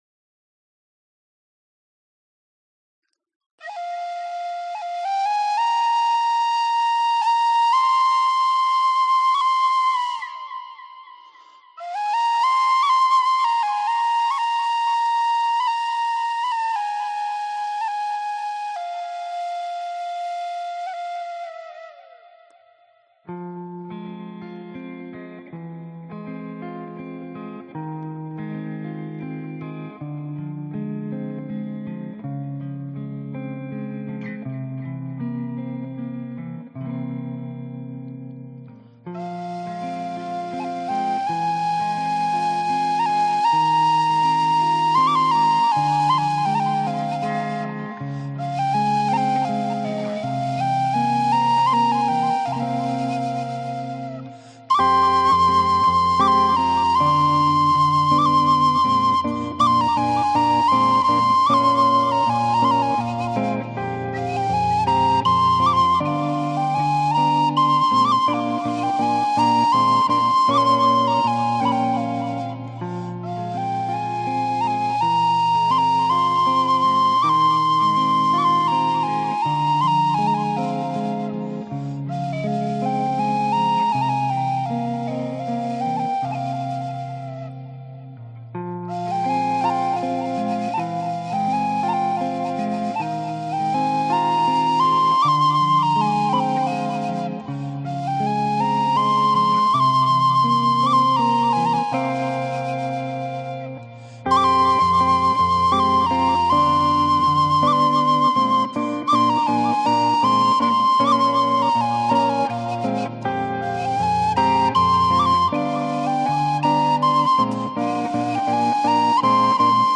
música folk